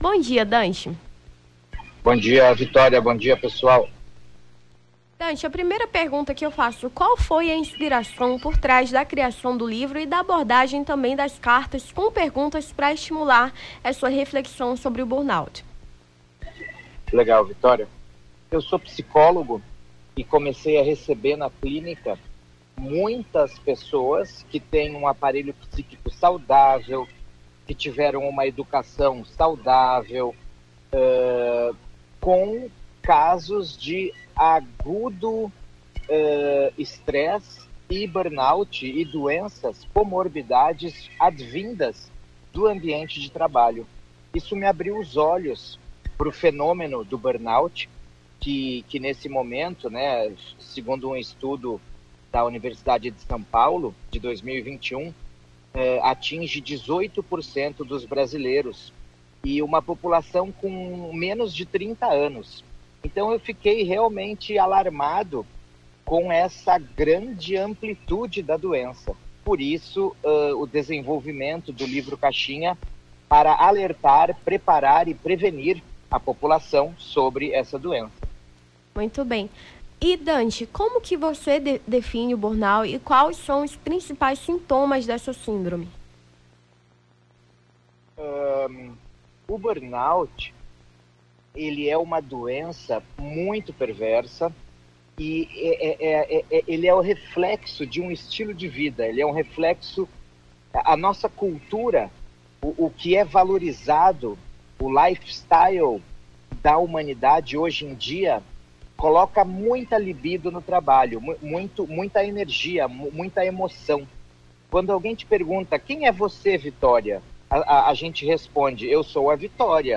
Nome do Artista - CENSURA - ENTREVISTA (COMO SAIR DO BURNOUT) 30-06-23.mp3